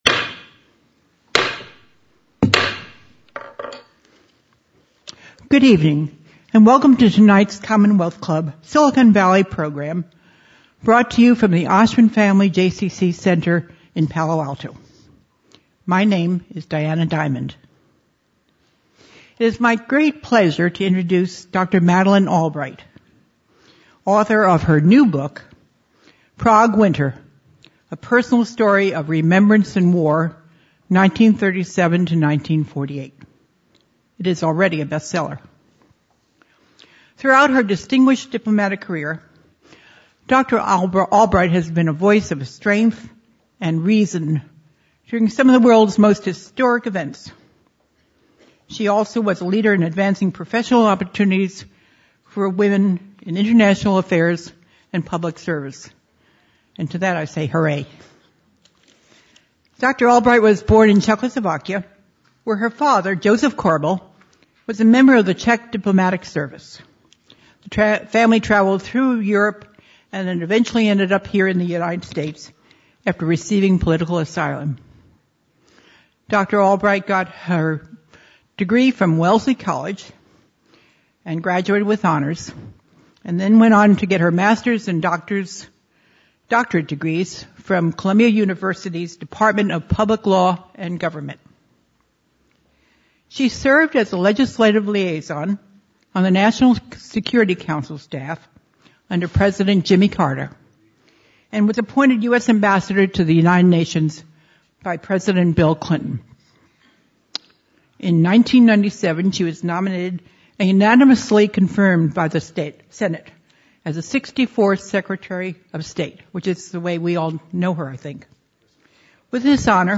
Madeleine K. Albright in Palo Alto